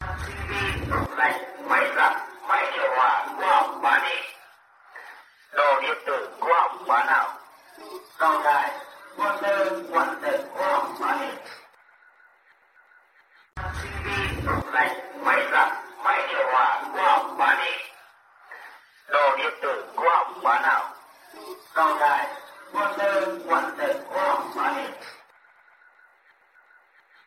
Tiếng rao mua Tivi, tủ lạnh, máy giặt, máy điều hòa cũ hỏng bán đi…
Description: Tiếng rao quen thuộc vang vọng khắp ngõ nhỏ: “Tivi, tủ lạnh, máy giặt, máy điều hòa cũ hỏng bán đi… Đồ điện tử hư hỏng bán nào? Mô-tơ, quạt trần hỏng bán đi…” như một giai điệu đời thường của người đồng nát. Âm thanh ấy gợi nhớ đến cảnh mưu sinh vất vả, len lỏi khắp phố phường, nhặt nhạnh những đồ cũ kỹ tưởng chừng vô dụng, góp phần dọn dẹp không gian và tái chế rác thải.
tieng-rao-mua-tivi-tu-lanh-may-giat-may-dieu-hoa-cu-hong-ban-di-www_tiengdong_com.mp3